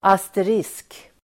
Ladda ner uttalet
Uttal: [aster'is:k]